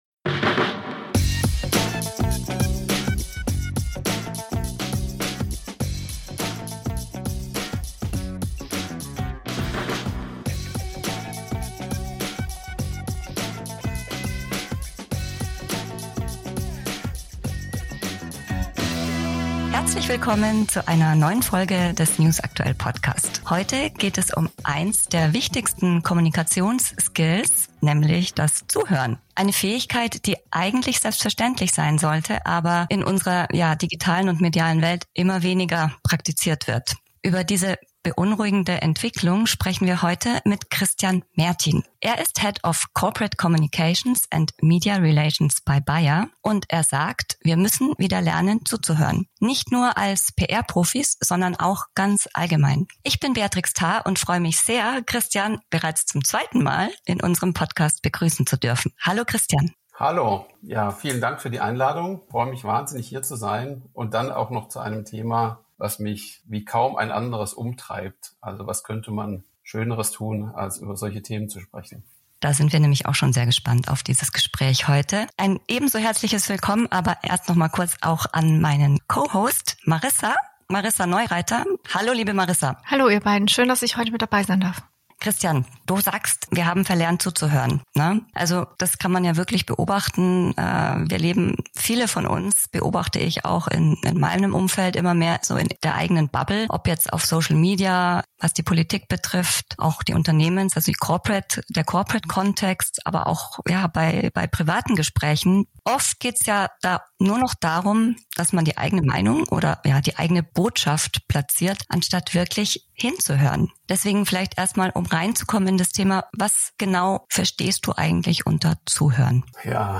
Am Host-Mikrofon